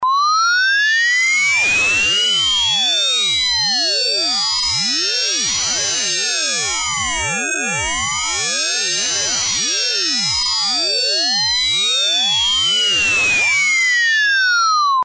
SineCube.mp3